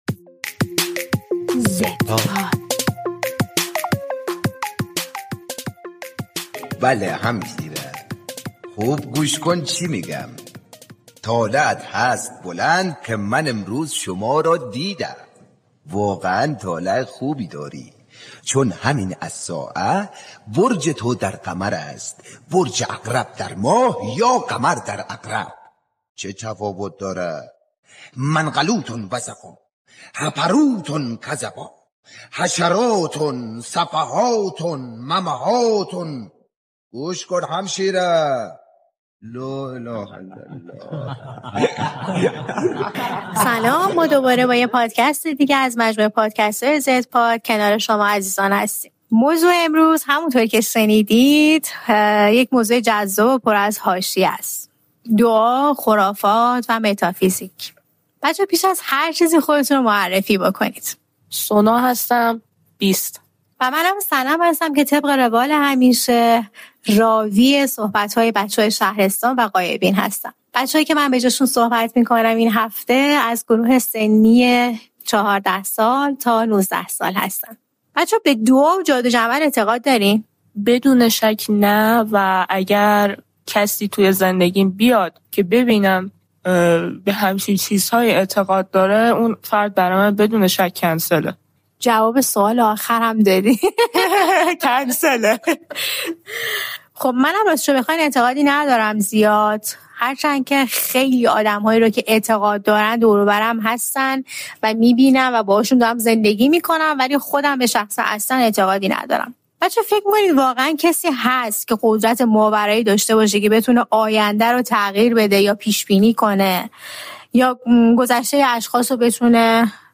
در این قسمت پادکست زدپاد، دو نفر از بچه‌های این نسل درباره خرفات، دعا، فال، رمالی و متافیزیک گفت‌وگو می‌کنند و از دیدگاه‌های این نسل درباره موضوع‌های مشابه می‌گویند.